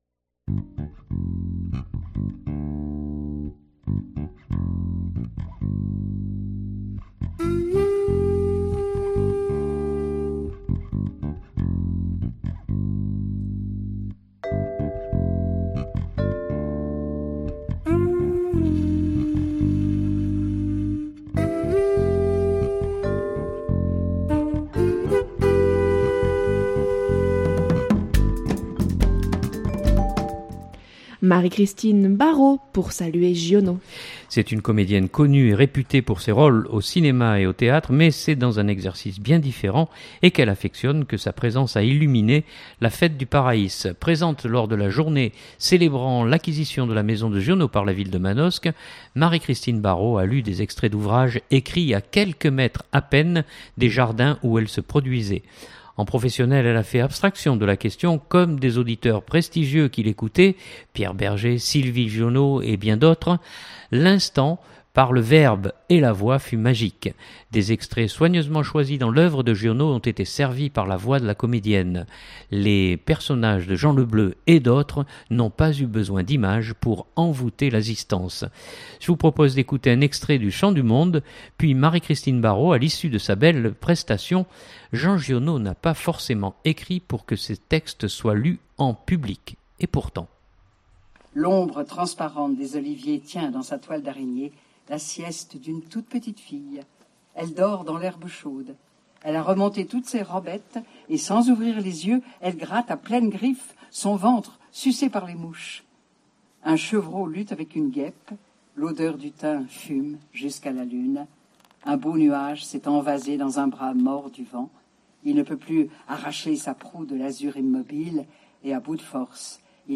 Présente lors de la journée célébrant l’acquisition de la maison de Giono par la Ville de Manosque, Marie-Christine Barrault a lu des extraits d’ouvrages écrits à quelques mètres à peine des jardins où elle se produisait.
L’instant, par le verbe et la voix fut magique !
Je vous propose d’écouter un extrait du « Chant du Monde » puis Marie-Christine Barrault à l’issue de sa belle prestation.